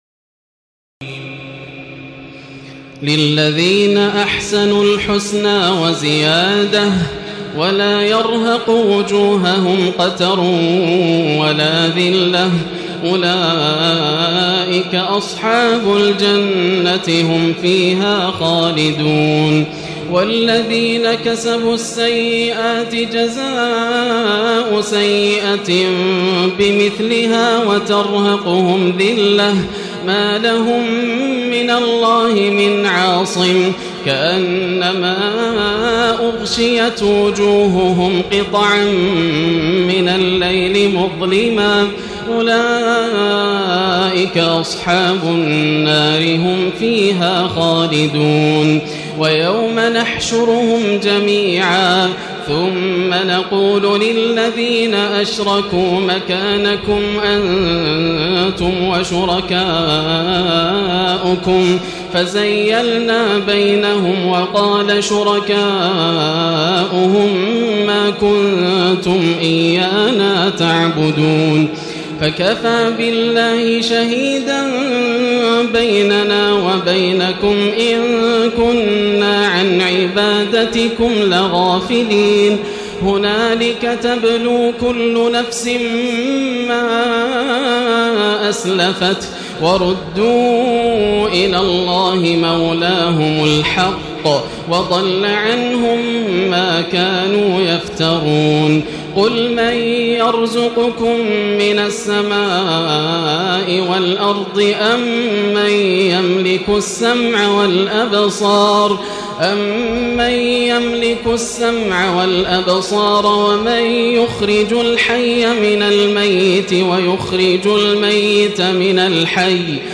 تراويح الليلة العاشرة رمضان 1436هـ من سورة يونس (26-109) Taraweeh 10 st night Ramadan 1436H from Surah Yunus > تراويح الحرم المكي عام 1436 🕋 > التراويح - تلاوات الحرمين